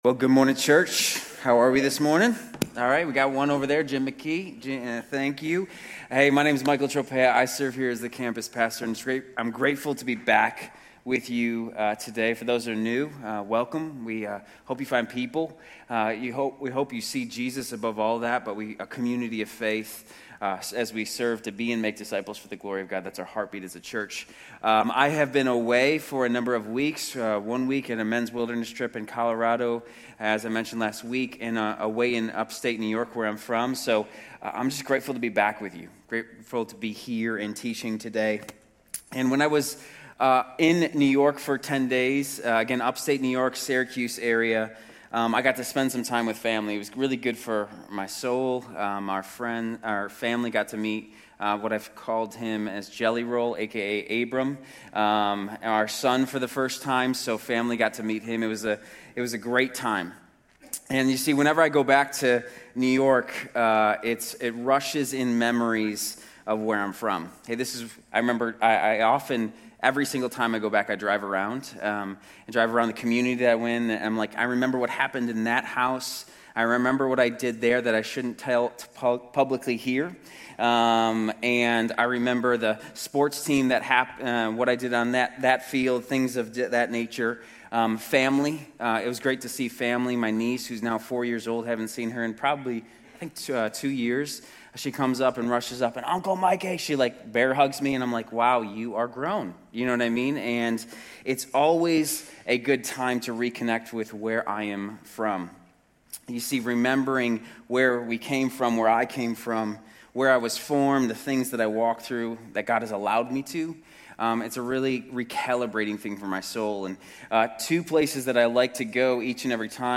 Grace Community Church University Blvd Campus Sermons 8_10 University Blvd Campus Aug 11 2025 | 00:37:42 1x 00:00 / 00:37:42 Subscribe Share RSS Feed Share Link Embed